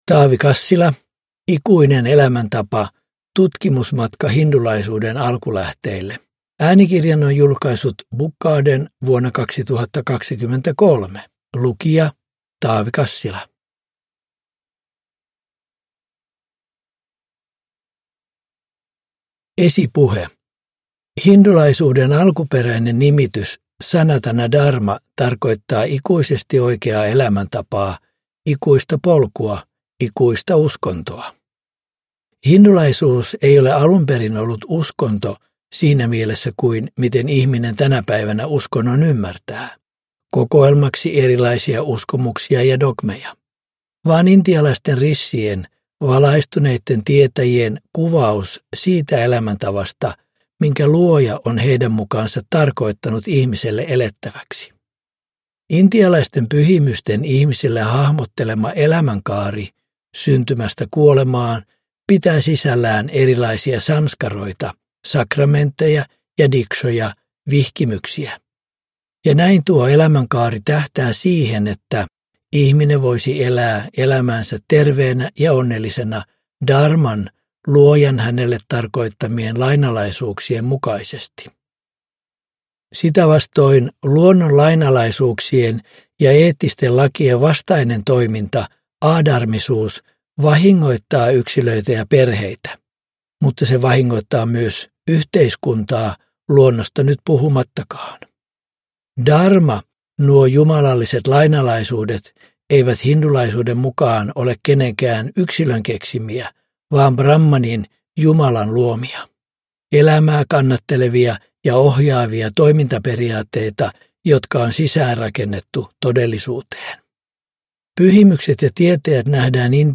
Ikuinen elämäntapa – Ljudbok